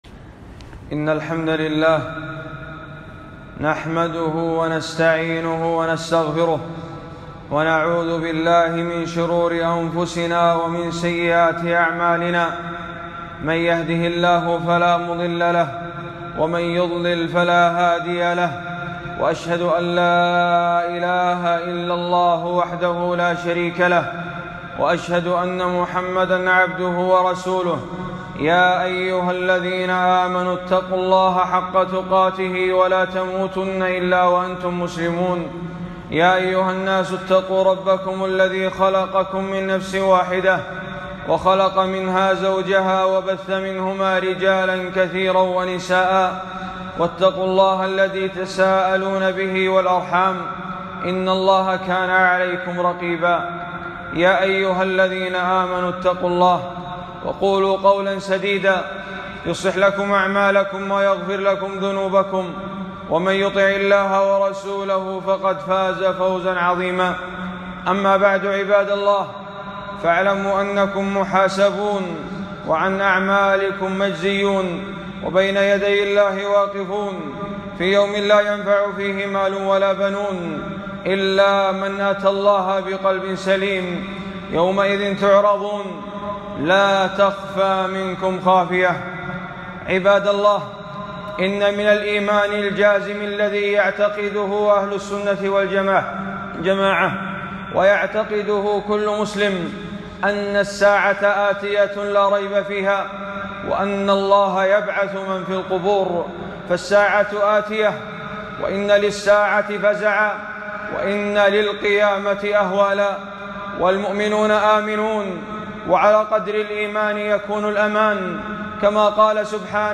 خطبة - ونضع الموازين القسط